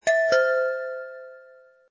EnterRoom.mp3